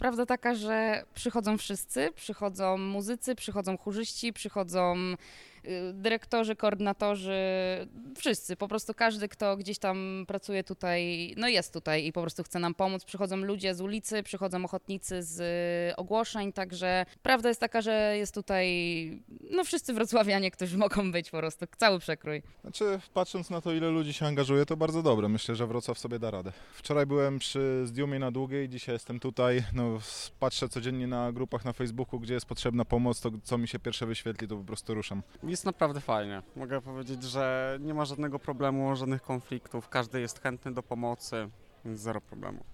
Kilku z nich opowiedziało nam o atmosferze i pracy.